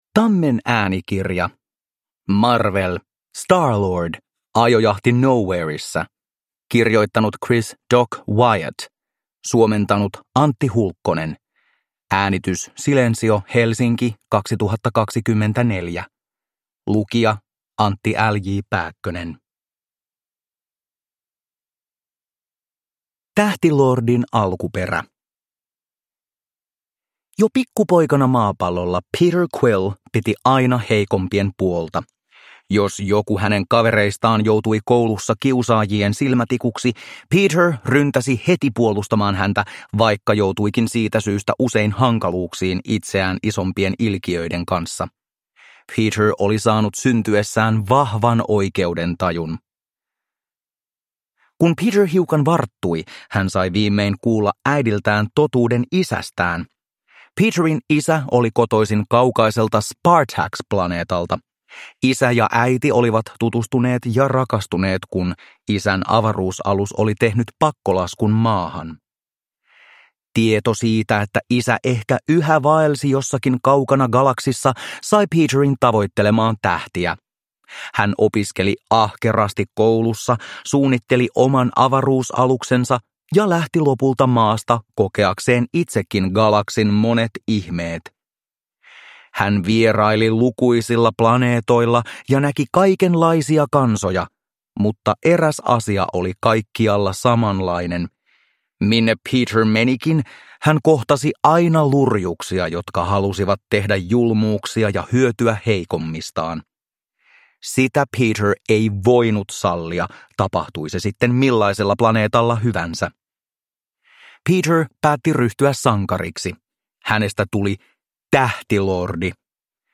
Marvel. Star-Lord. Ajojahti Knowheressa – Ljudbok